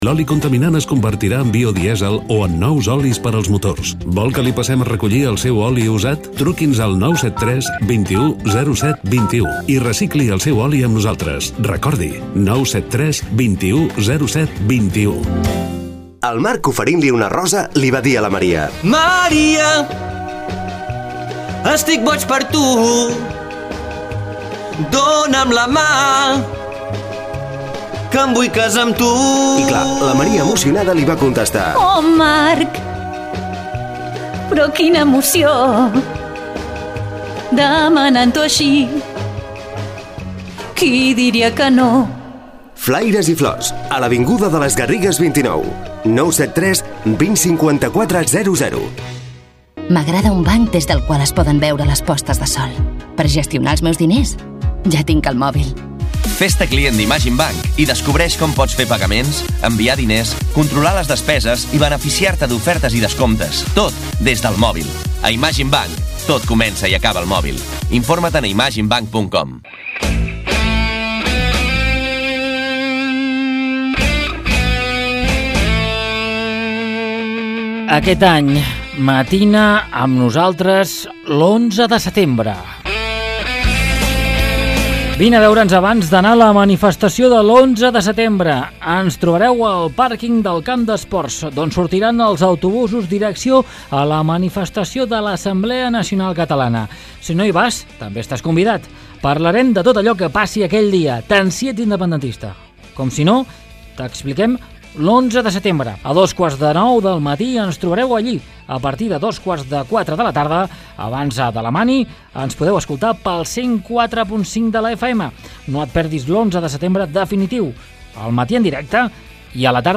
Tertúlia de ràdio UA1.